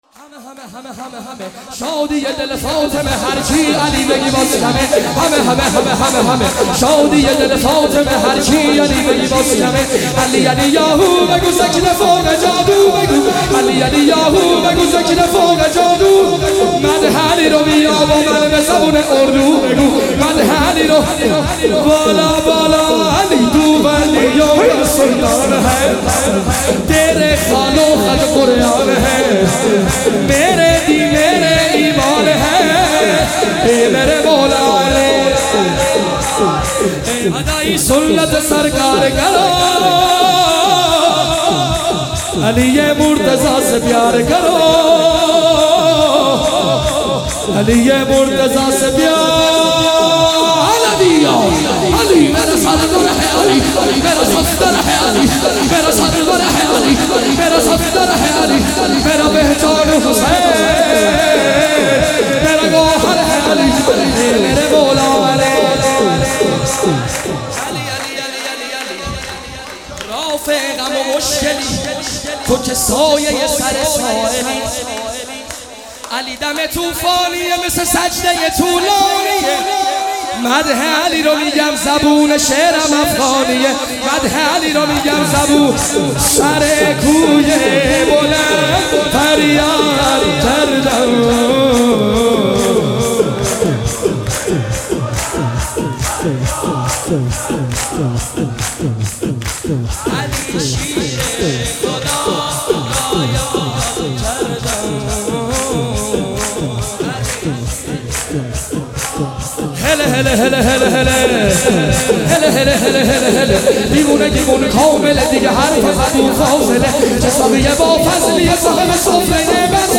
مولودی خوانی ولادت حضرت معصومه (س)